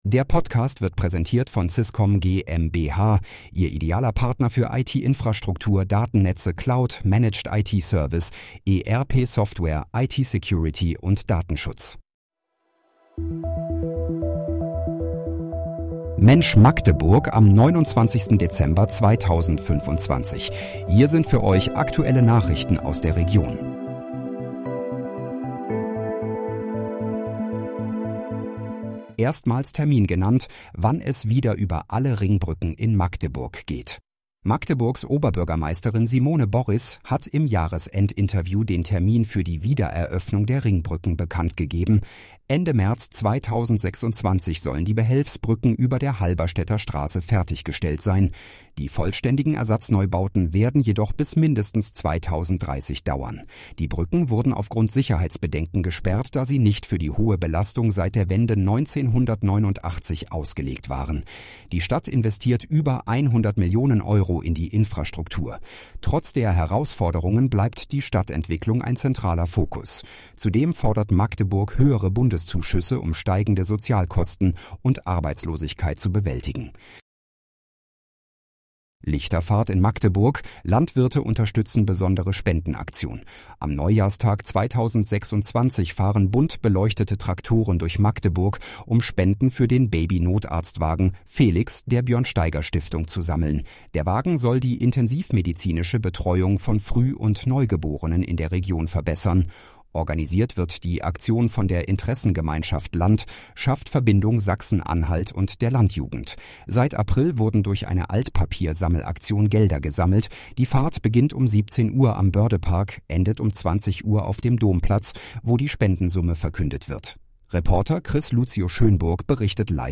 Mensch, Magdeburg: Aktuelle Nachrichten vom 29.12.2025, erstellt mit KI-Unterstützung
Nachrichten